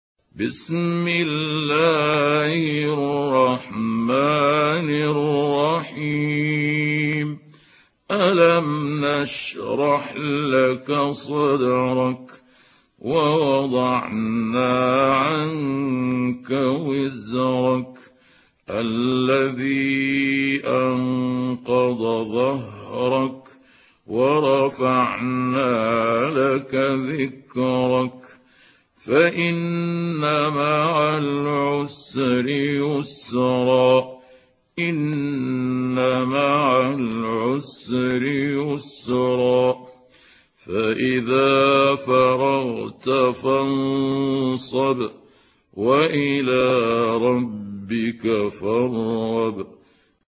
سورة الشرح | القارئ محمود عبد الحكم